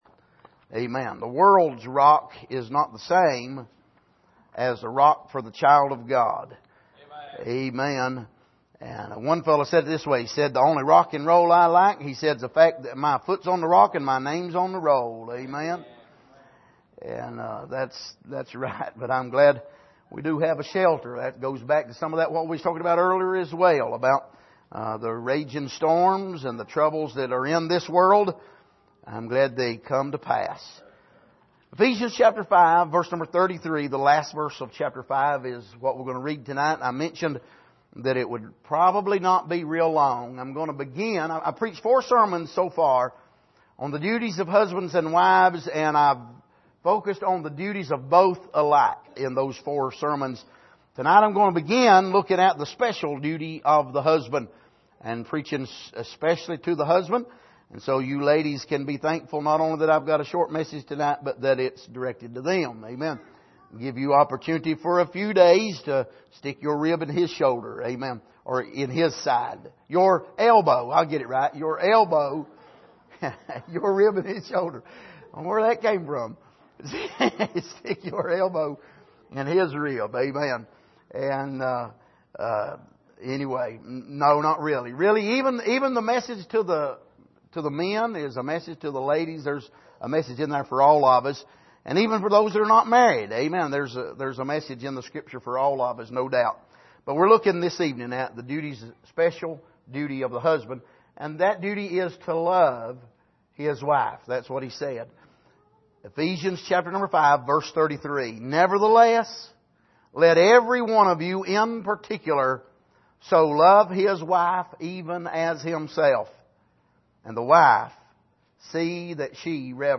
Passage: Ephesians 5:33 Service: Sunday Evening